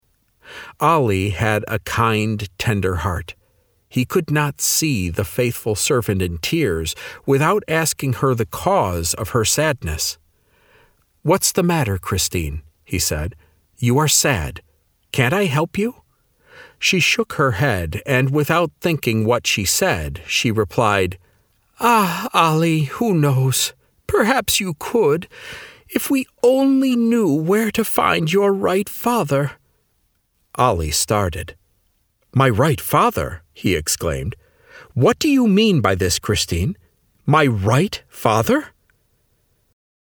Audiobook: Grinder's Apprentice - MP3 download - Lamplighter Ministries
The-Grinders-Apprentice-Audiobook-Sample.mp3